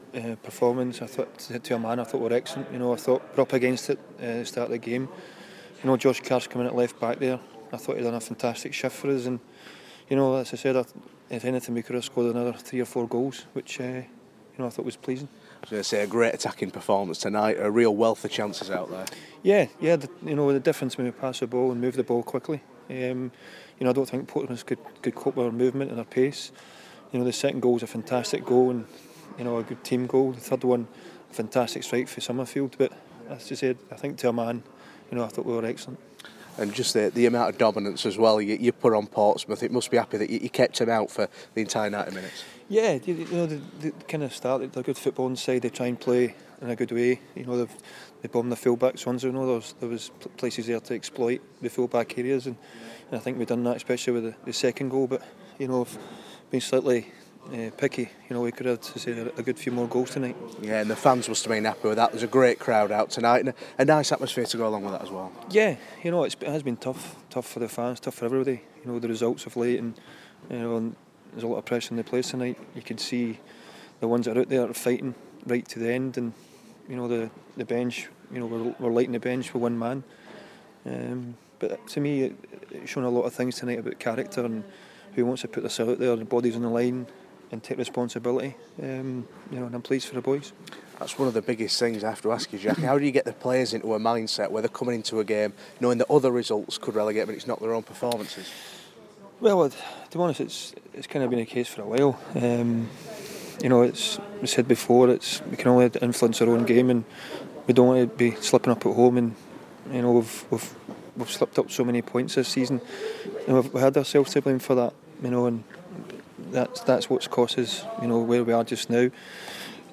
speaks to the York City manager as the Minstermen beat promotion hopefuls Portsmouth to avoid relegation for the moment.